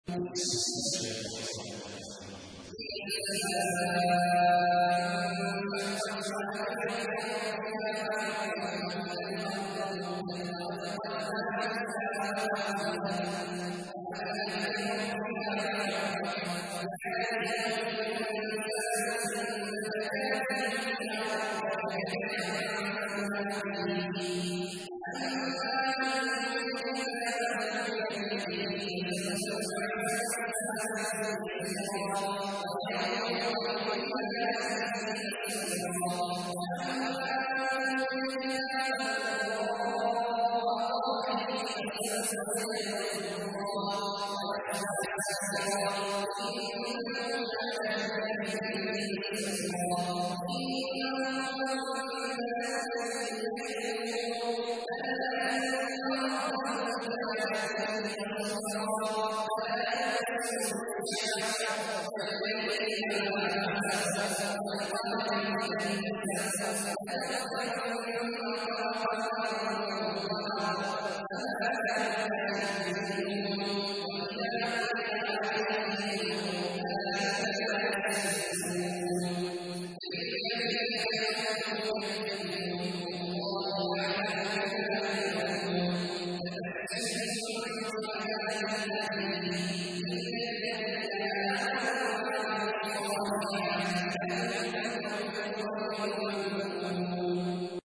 تحميل : 84. سورة الانشقاق / القارئ عبد الله عواد الجهني / القرآن الكريم / موقع يا حسين